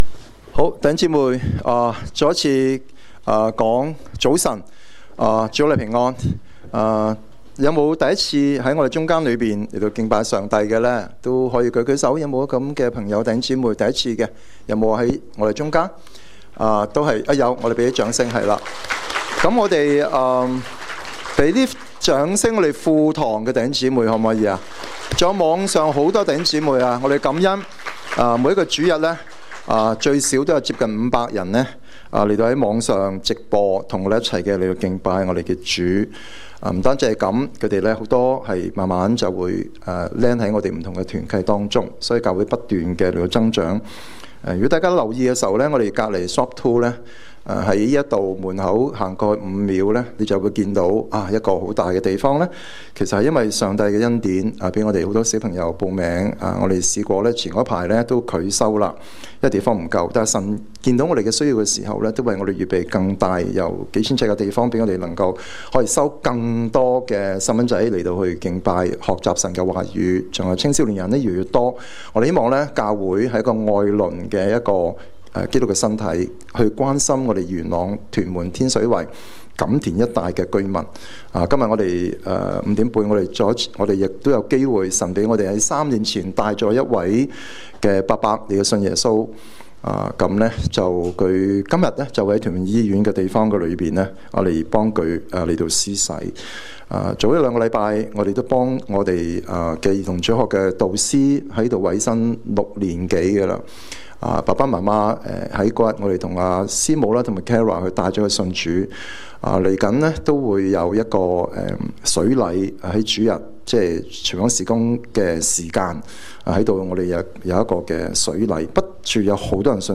證道集